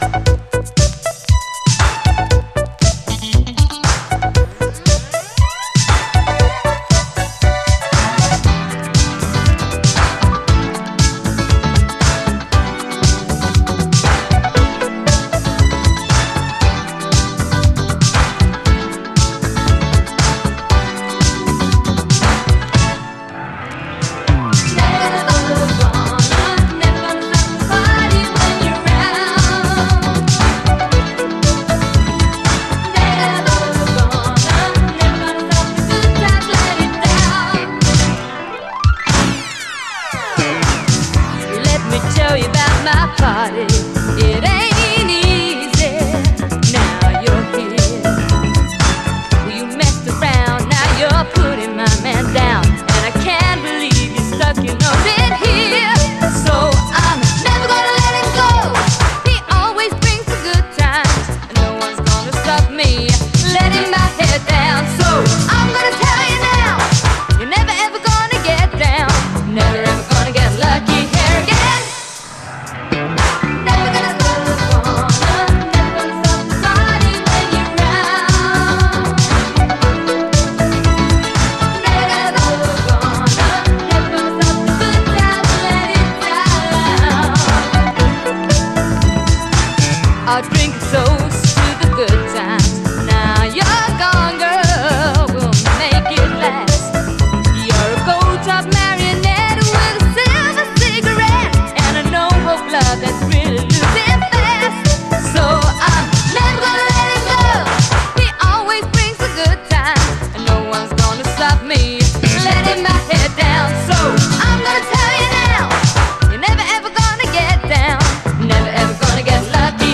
SOUL, 70's～ SOUL, DISCO, 7INCH
キャッチー＆ビューティフルなUK産シンセ・モダン・ブギー〜ブリット・ファンク！